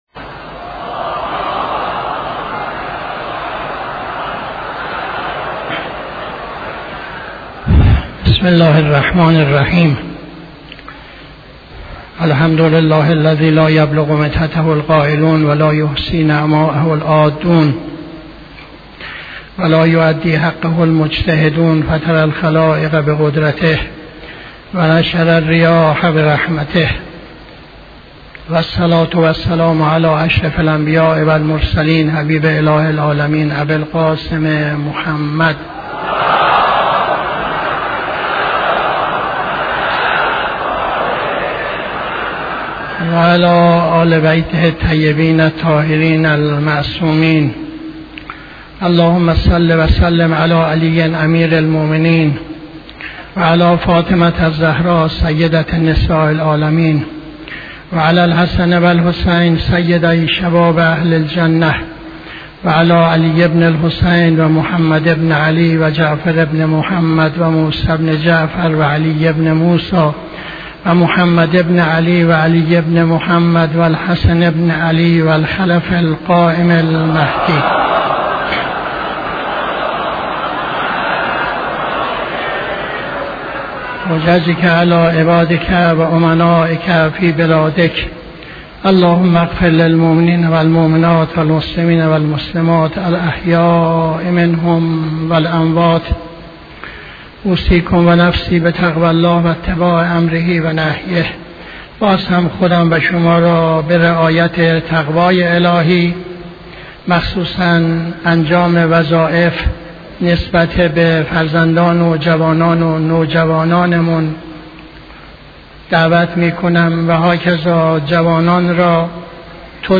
خطبه دوم نماز جمعه 10-08-80